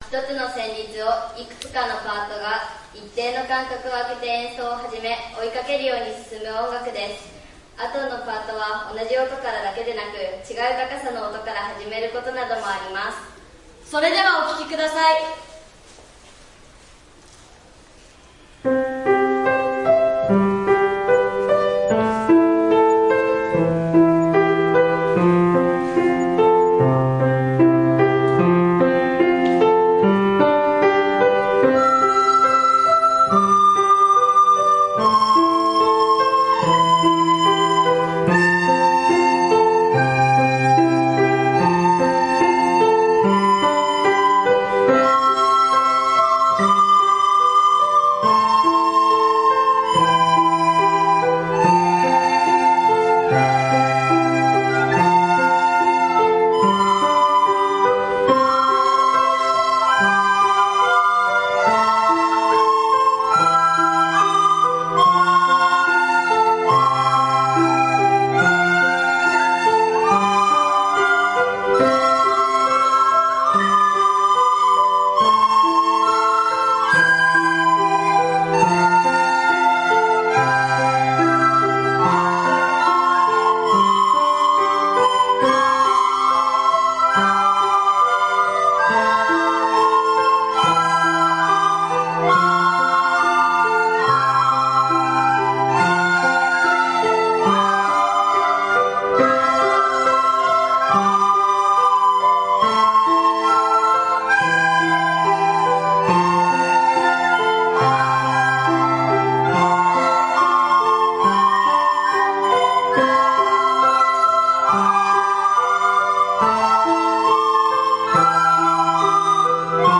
6年生♪音楽発表会♫
それぞれの旋律が重なり、とてもきれいな音色が響きました音楽
３階多目的室の広い空間を使ってソーシャルディスタンスを十分とりながら演奏しました。